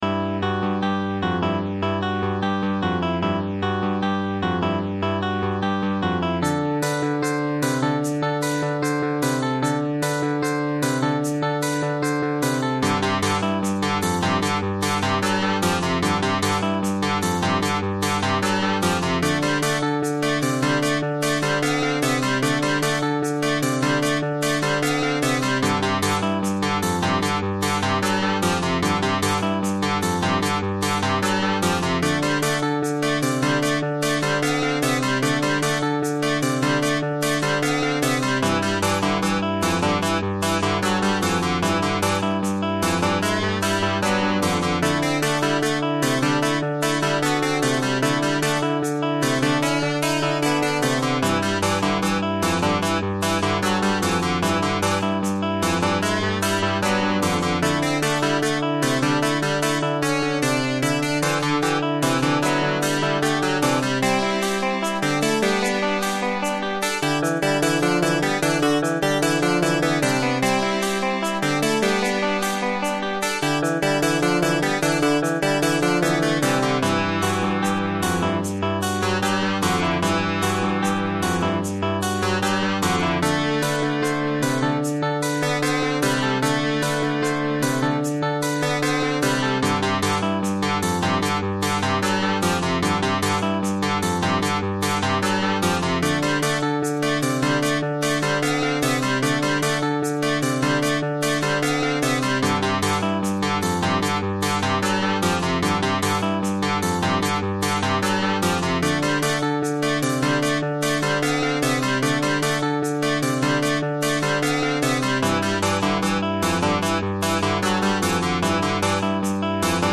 Will you choose this awful background music...